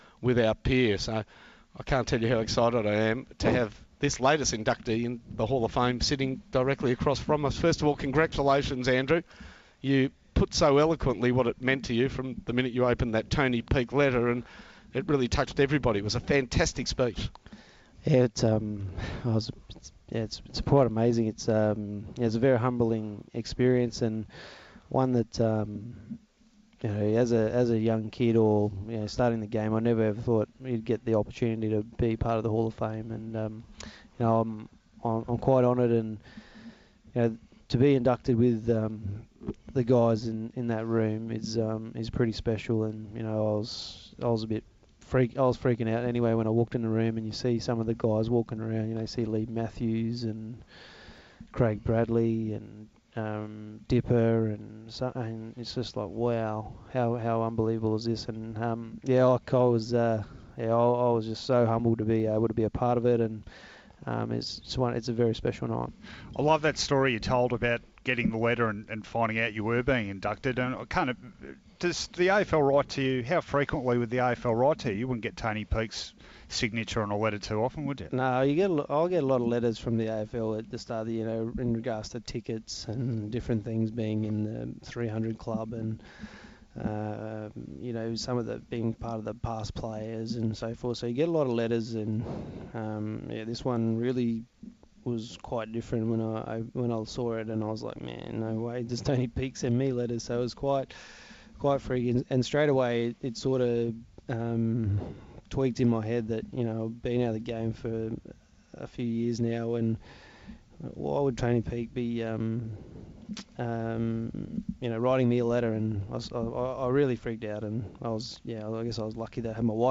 Andrew McLeod interviewed by SEN after his Australian Football Hall of Fame induction